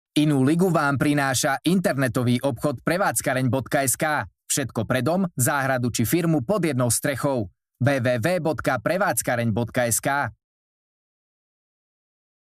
Hlas do reklamy (voiceover)
(vyčistenie nahrávky od nádychov rôznych ruchov a zvuková postprodukcia je samozrejmosťou)